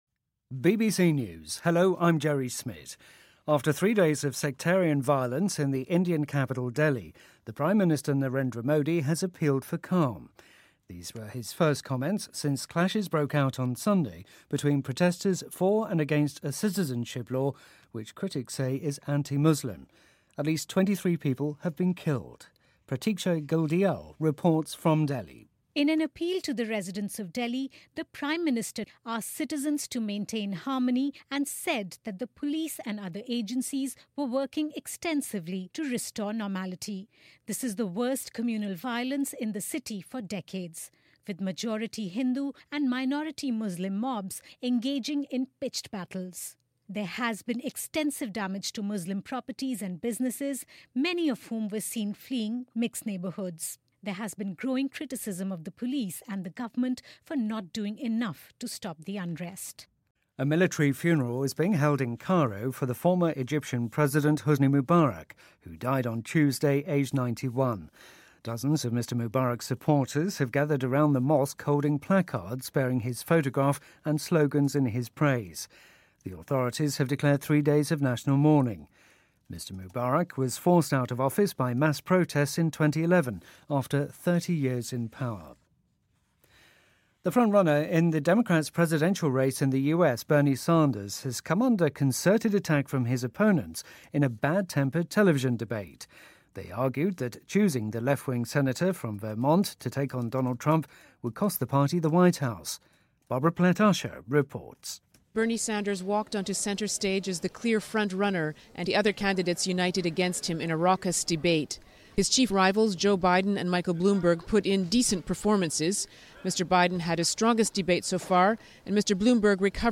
英音听力讲解:埃及前总统穆巴拉克去世